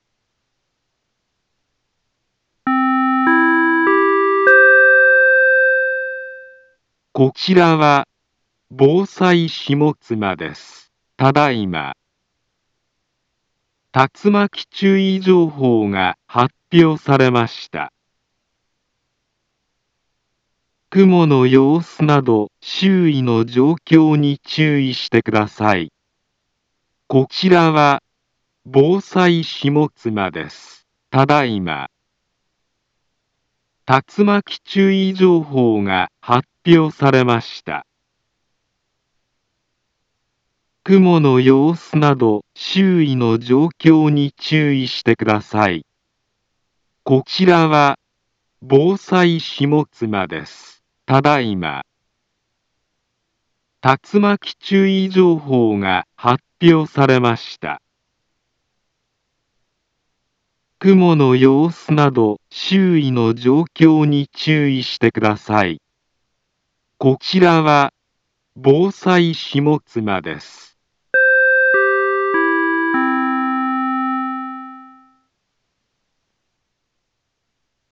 Back Home Ｊアラート情報 音声放送 再生 災害情報 カテゴリ：J-ALERT 登録日時：2024-07-22 19:44:20 インフォメーション：茨城県北部、南部は、竜巻などの激しい突風が発生しやすい気象状況になっています。